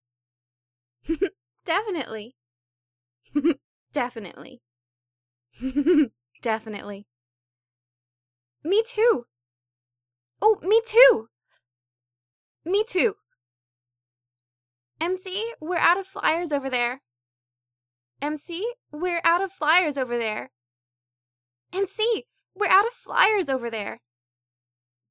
Voice Actor
Female Student A (Rose)